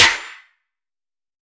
BWB VAULT SNARE (EdmTrap).wav